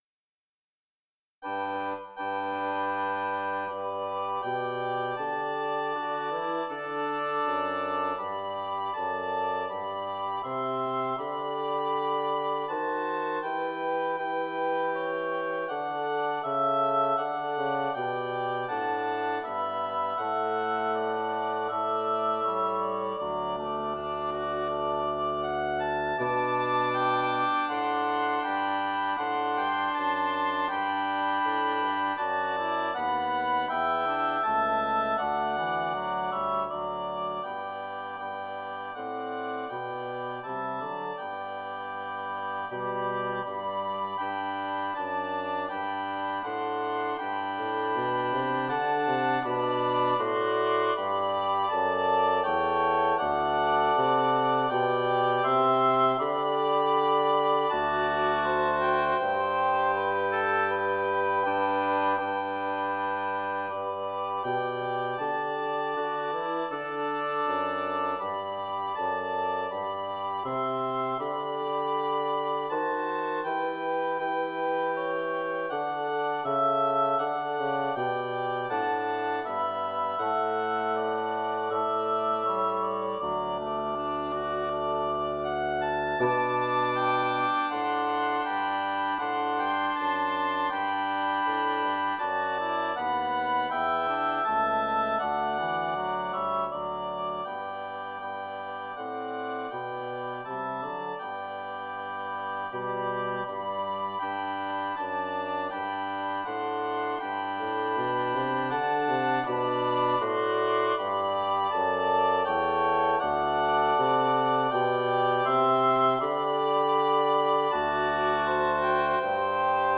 Double Reed Ensemble – Christmas music
Instrumentation: Double Reed Ensemble
tags: oboe music, bassoon music. Christmas music